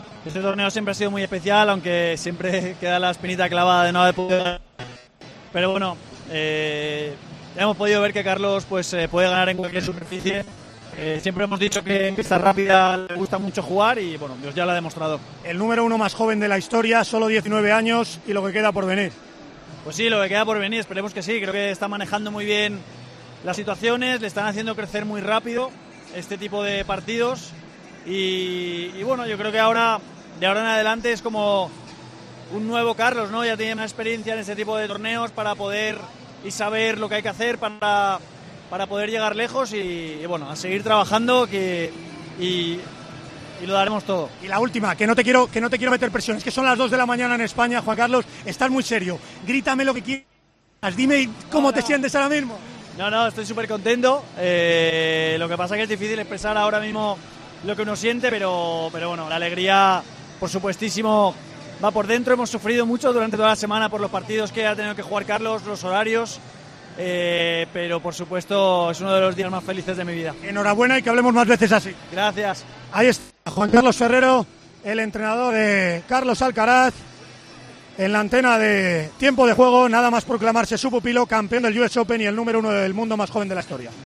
AUDIO: Hablamos con el entrenador de Carlos Alcaraz tras lograr un histórico US Open y ser número 1 del mundo.